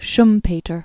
(shmpā-tər), Joseph Alois 1883-1950.